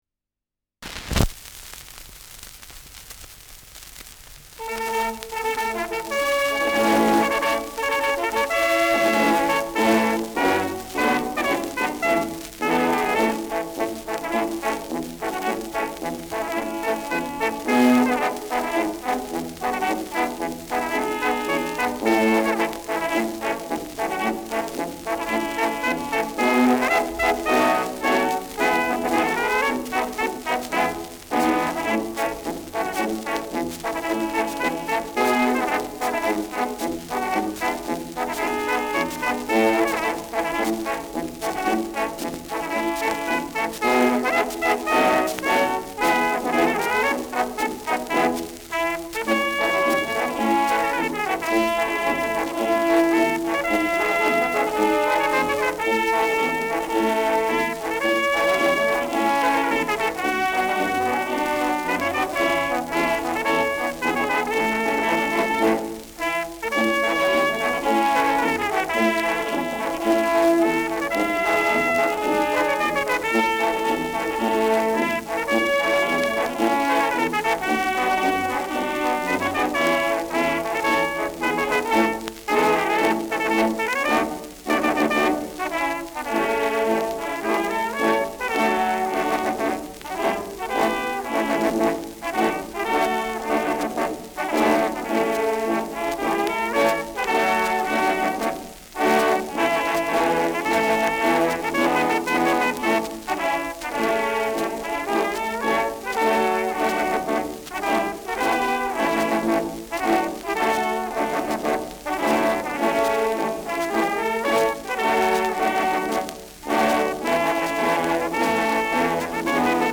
Schellackplatte
Tonrille: Kratzer 1 Uhr Stärker : Berieb 9 Uhr Stark
präsentes Rauschen
Kapelle Greiß, Nürtingen (Interpretation)
[Stuttgart] (Aufnahmeort)